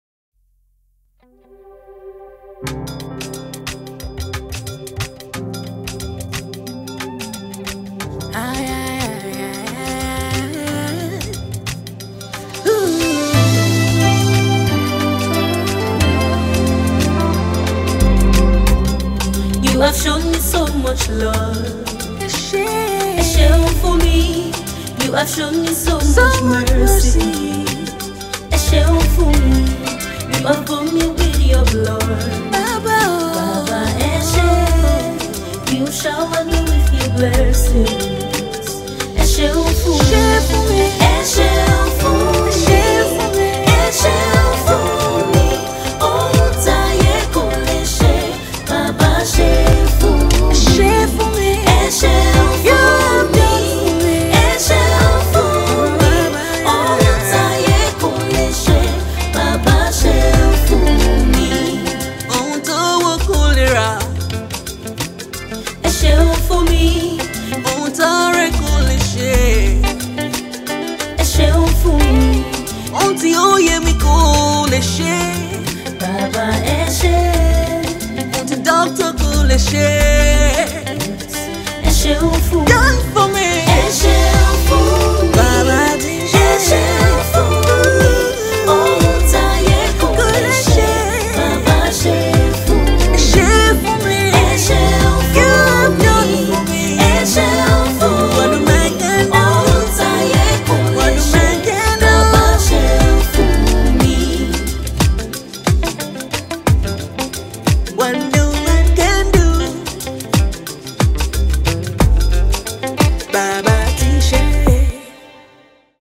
Nigerian talented gospel singer and songwriter